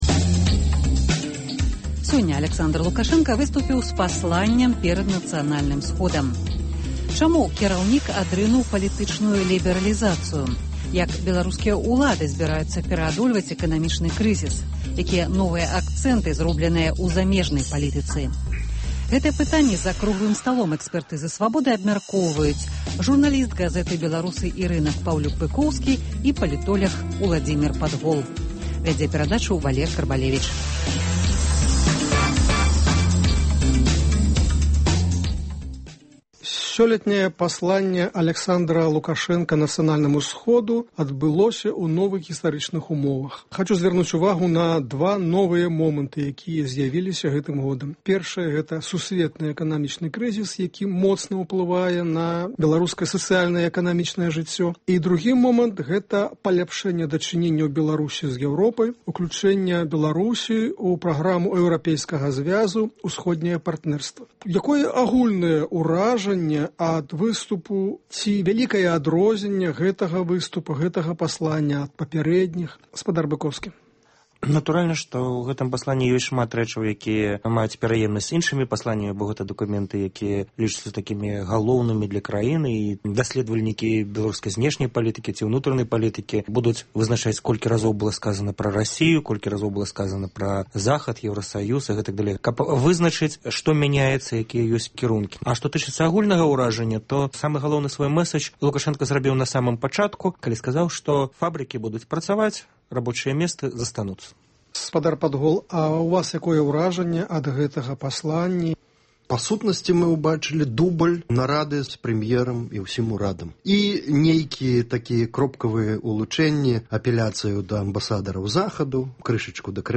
Як беларускія ўлады зьбіраюцца пераадольваць эканамічны крызыс? Чаму Лукашэнка адрынуў палітычную лібэралізацыю? Якія новыя акцэнты ў замежнай палітыцы? Гэтыя пытаньні абмяркоўваюць за круглым сталом